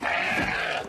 animalia_sheep_death.ogg